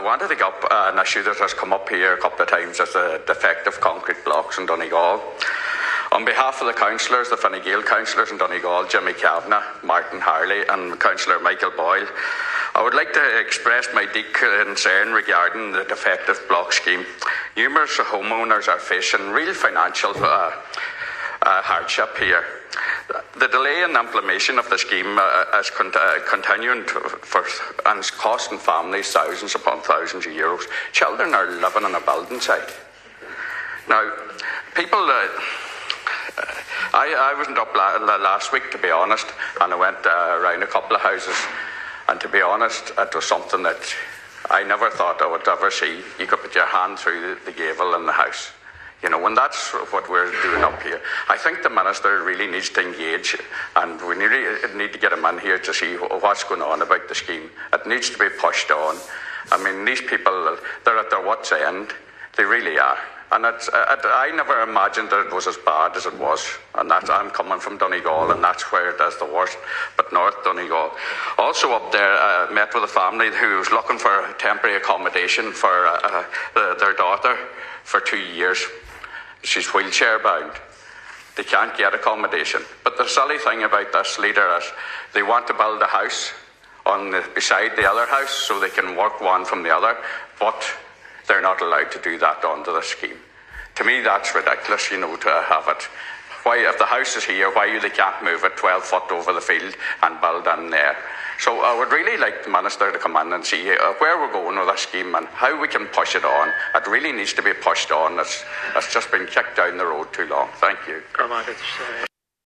Fine Gael Senator Manus Boyle yesterday took the opportunity to tell the chamber the story of the family who have a daughter who uses a wheelchair and cannot find alternative accommodation to suit her needs.
Senator Boyle says the state of the homes is something that needs to be seen to be believed: